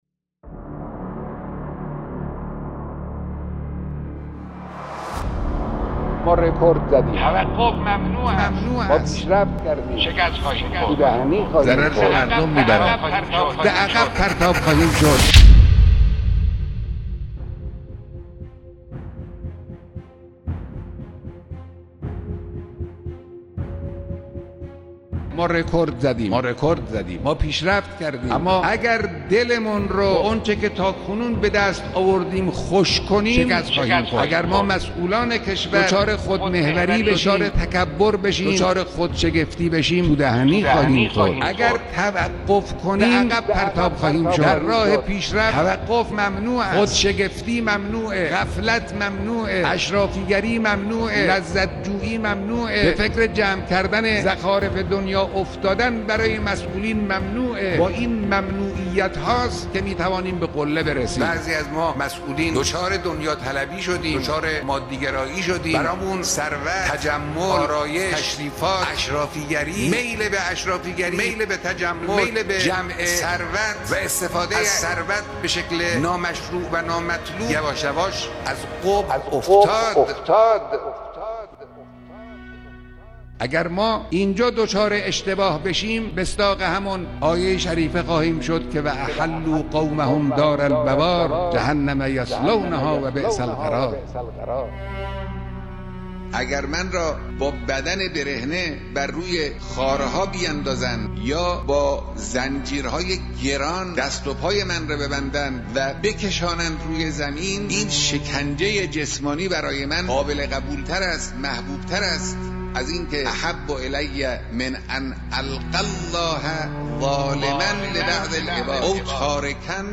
در راستای تاکیدات رهبر معظم انقلاب مبنی بر مبارزه با اشرافی‌گری، لذت جویی و غفلت زدگی مسئولین، پایگاه اطلاع رسانی دفتر مقام معظم رهبری اقدام به بازخوانی بیانات معظم‌له در قالب کلیپ صوتی «شکست خواهیم خورد ...» نموده است.